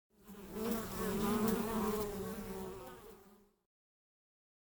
bee.ogg